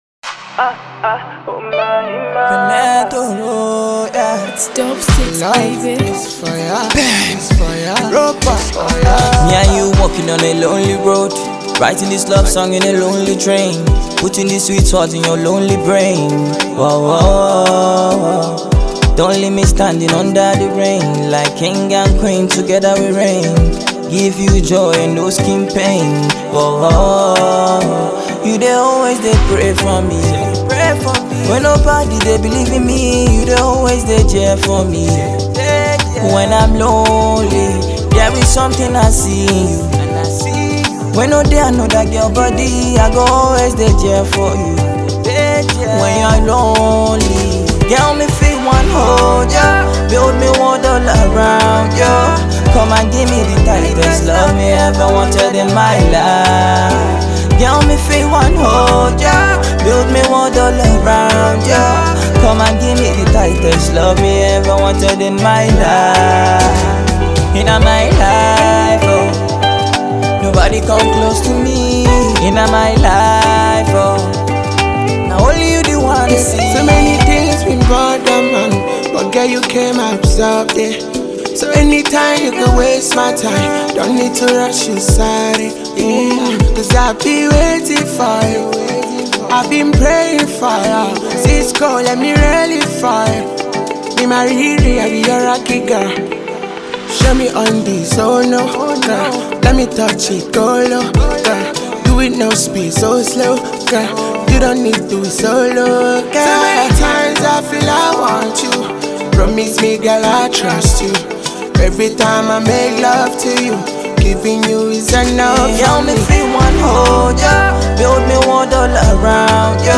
a tuneful remix
love song